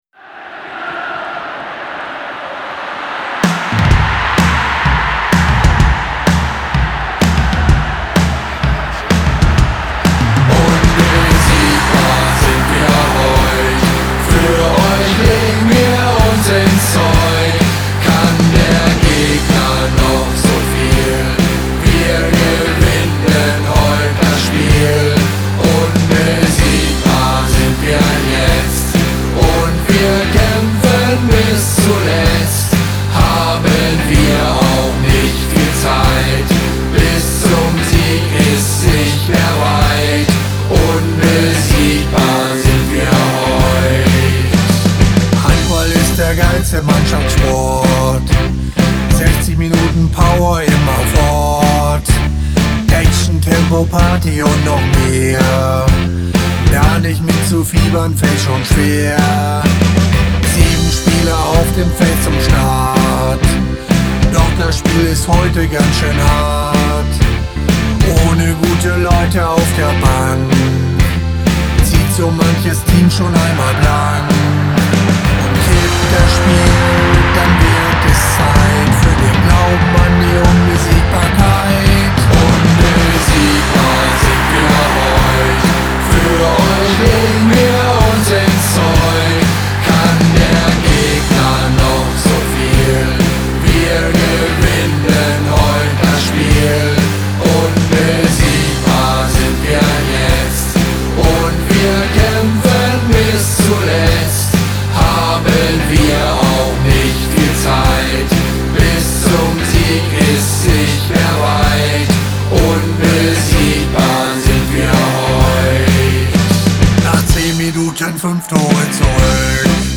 Schon vor einiger Zeit habe ich als aktiver Handballer den Song "Unbesiegbar" aufgenommen - eine Hommage an unseren tollen Handballsport. Diese neue Handball-Hymne stelle ich Euch hier als mp3-File zum Download (bitte hier zum Download klicken [8.391 KB] ) zur Verfügung - und ich hoffe, dass er Euch Glück und vielleicht auch die Stimmung bringt, ein absolutes TOP-Ergebnis zu erreichen.